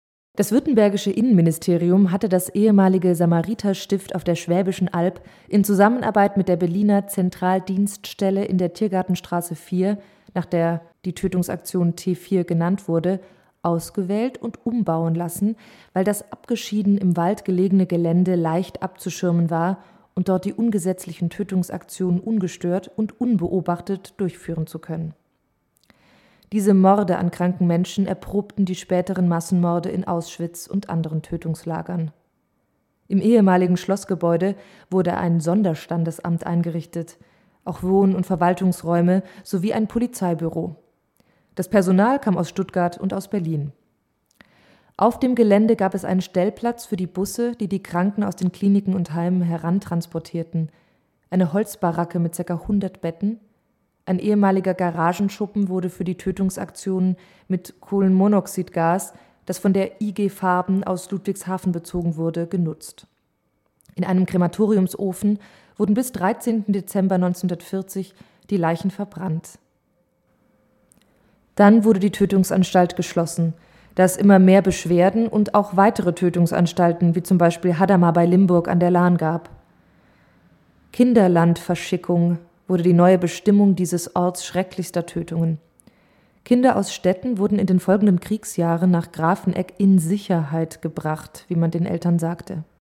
Gesprochen von